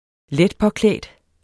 Udtale [ ˈlεdpʌˌklεˀdə ]